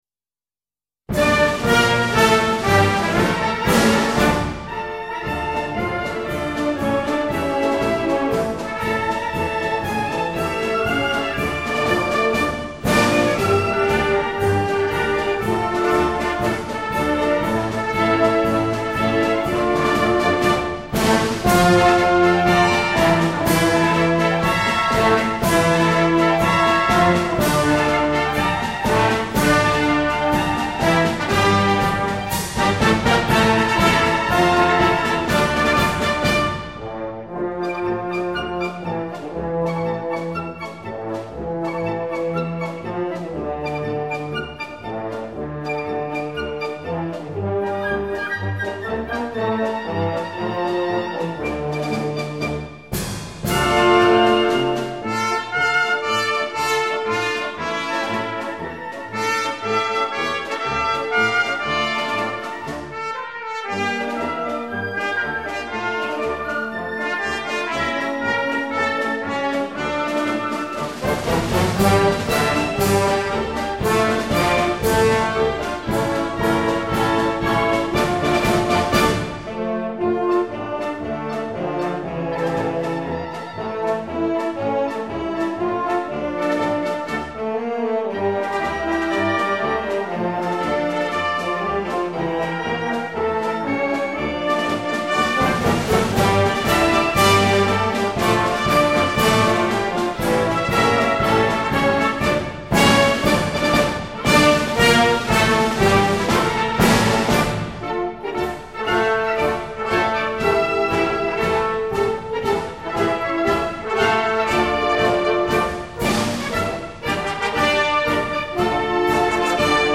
它以降E小调贯穿全曲，一开始就给人以某种悲怆的感觉，但旋律激动人心，声声催人奋进；